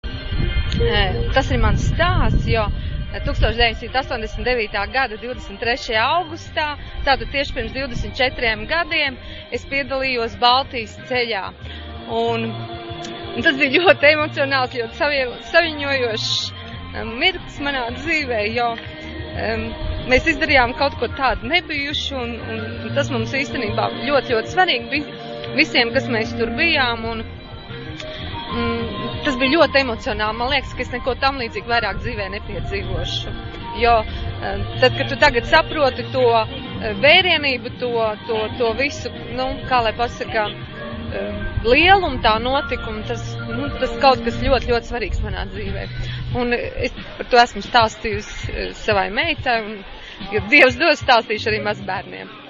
Atmiņu stāsts ierakstīts Eiropas digitālās bibliotēkas "Europeana" un Latvijas Nacionālās bibliotēkas organizētajās Baltijas ceļa atceres dienās, kas notika 2013. gada 23. un 24. augustā Rīgā, Esplanādē.